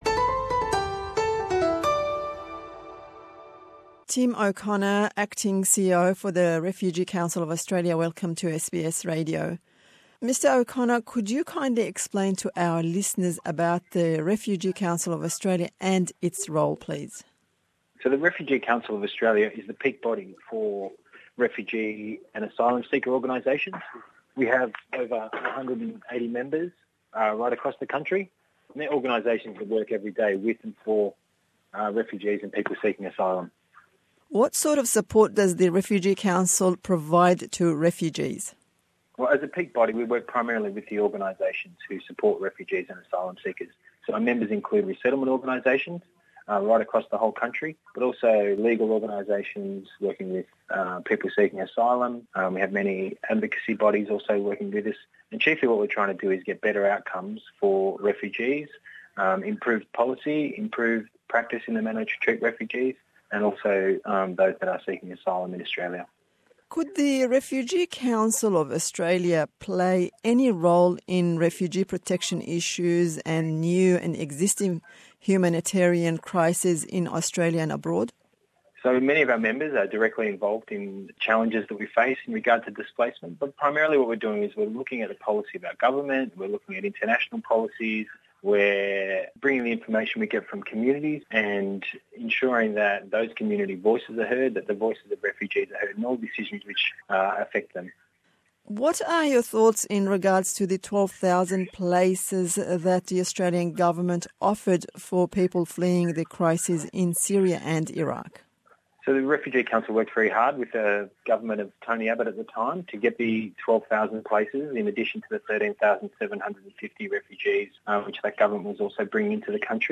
Me hevpeyvînek